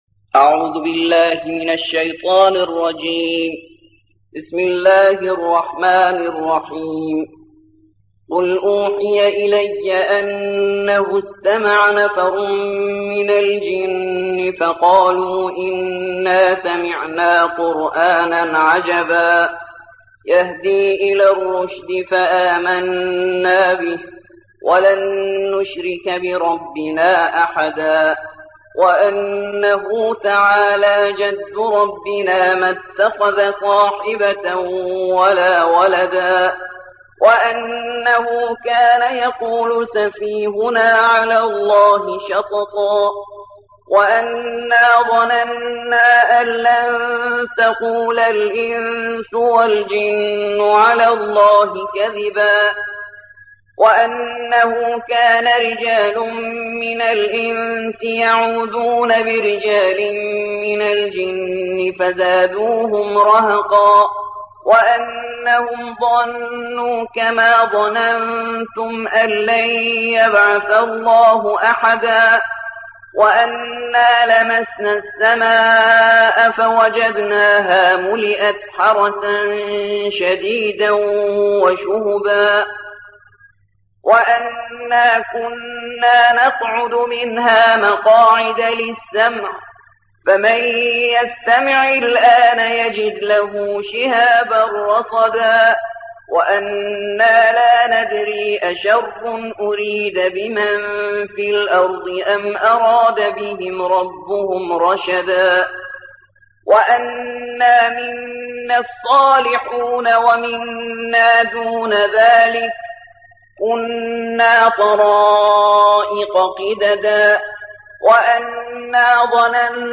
72. سورة الجن / القارئ